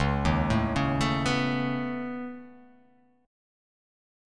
Db7sus2#5 Chord